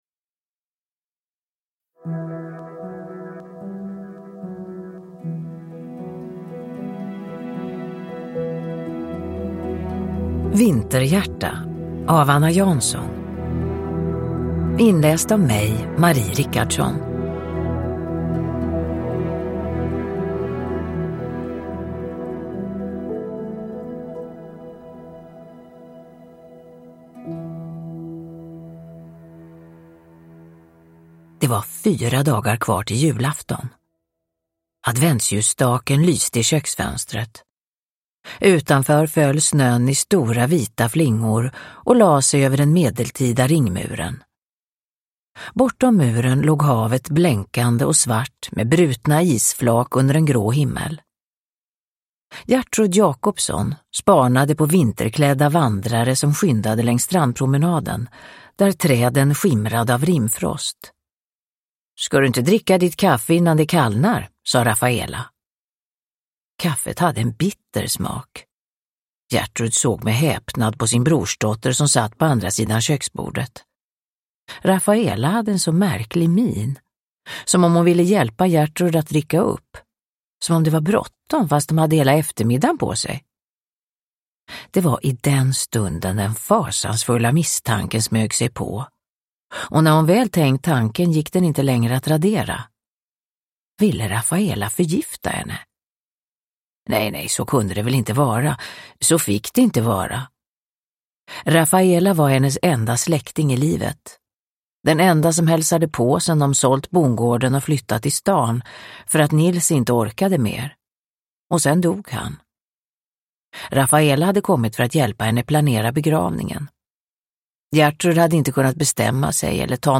Vinterhjärta – Ljudbok – Laddas ner
Uppläsare: Marie Richardson